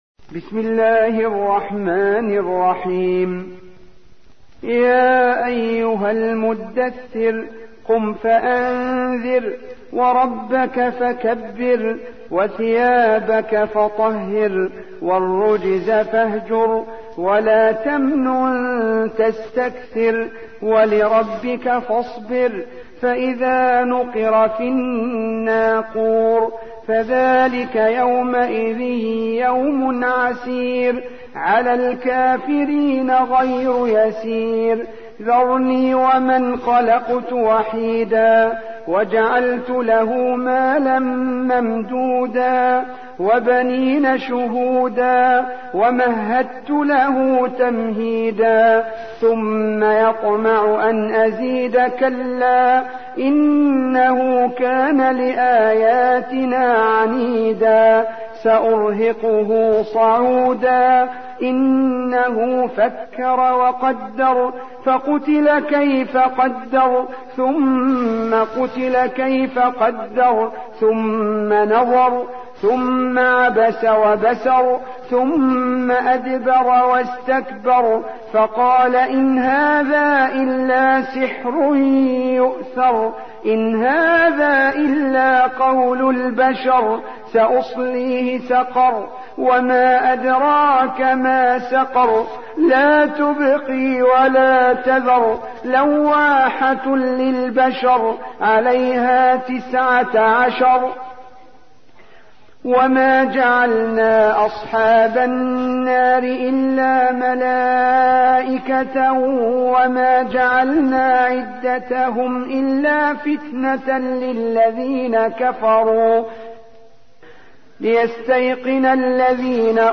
74. سورة المدثر / القارئ